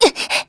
Isaiah-Vox_Damage_kr.wav